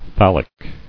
[thal·lic]